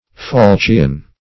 \Faul"chion\